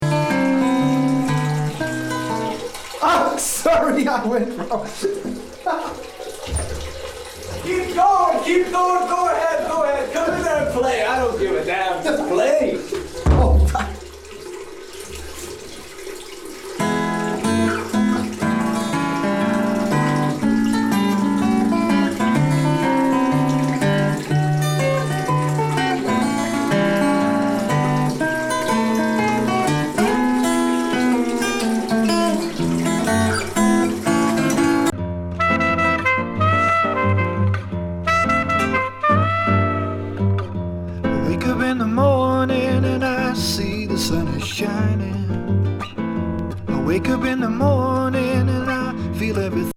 トイレ排水音から